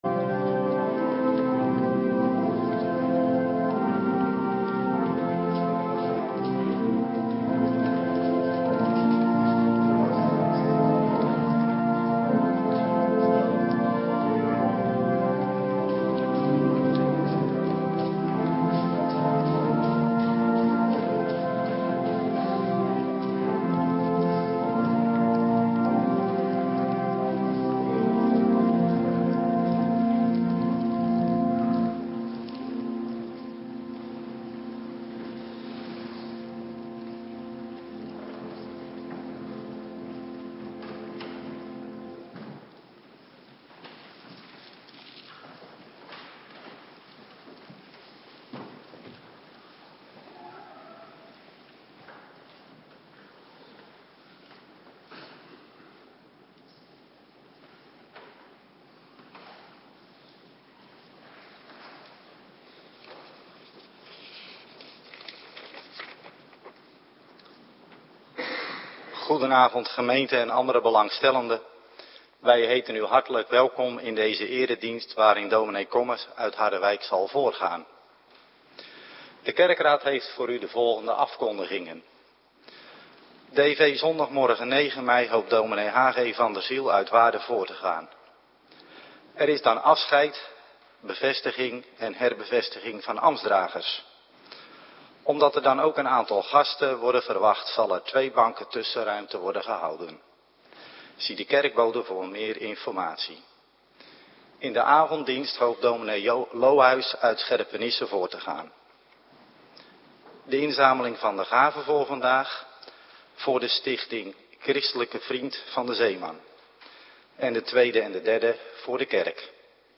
Avonddienst - Cluster 3
Locatie: Hervormde Gemeente Waarder